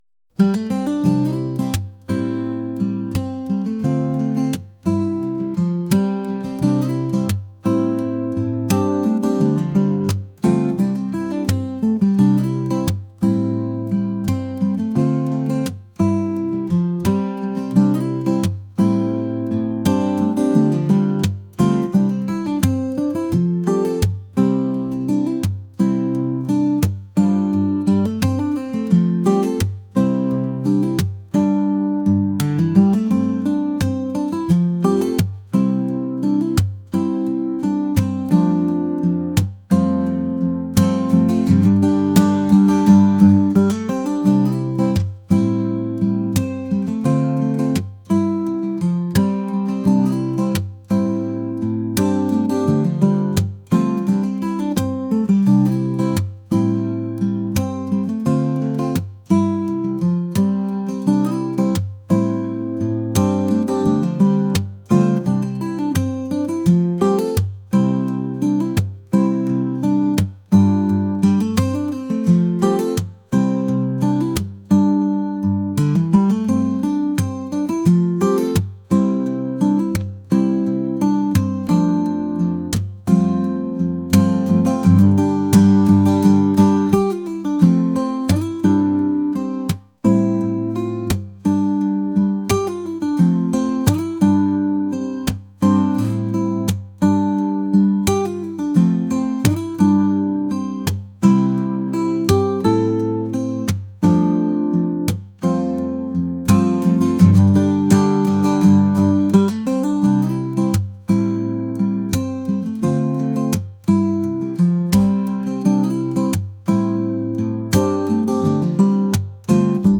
acoustic | folk | soulful